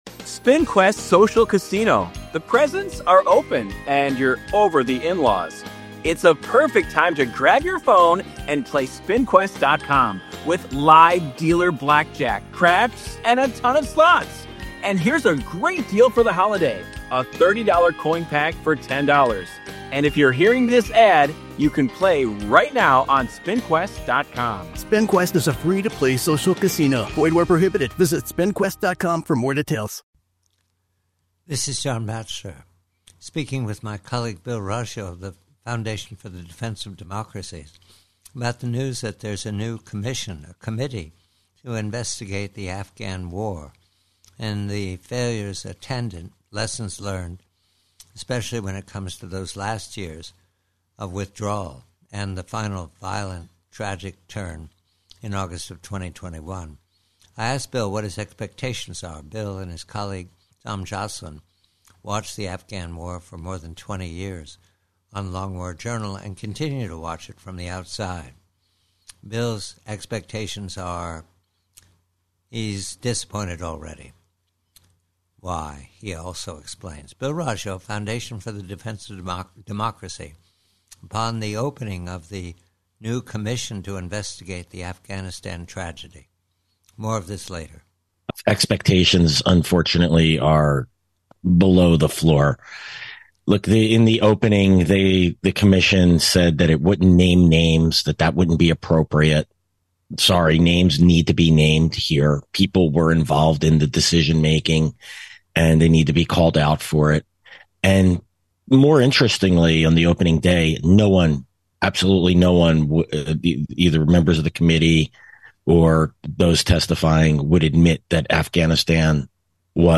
PREVIEW: AFGHANISTAN: Conversation with colleague